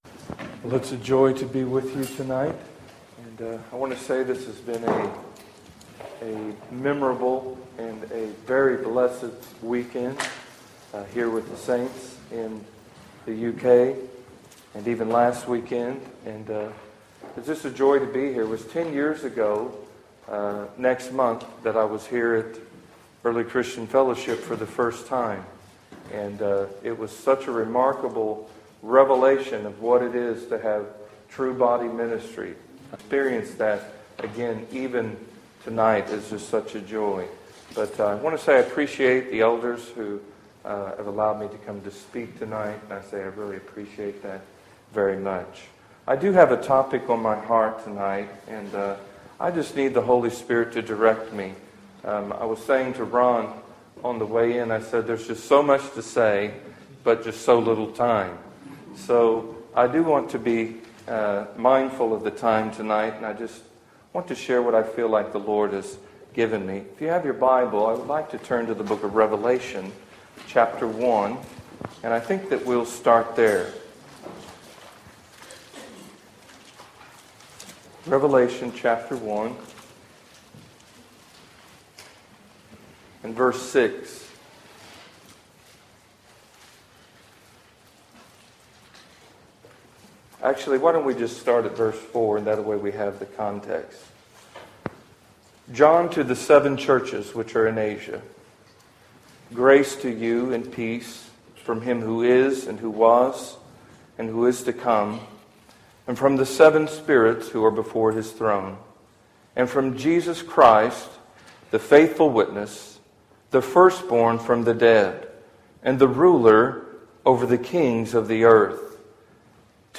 Featured Sermon Series